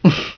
DATrem2_Laugh.wav